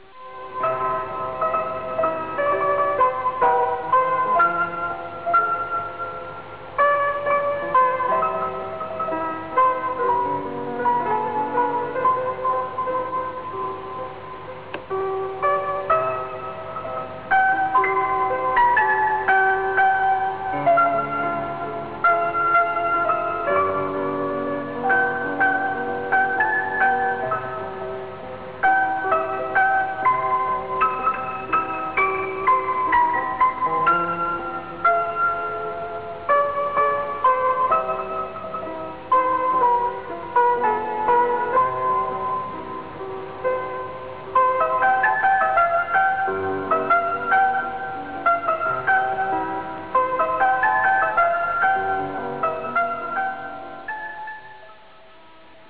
Original Track Music (1.00)